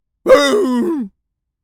pgs/Assets/Audio/Animal_Impersonations/seal_walrus_death_01.wav at 7452e70b8c5ad2f7daae623e1a952eb18c9caab4
seal_walrus_death_01.wav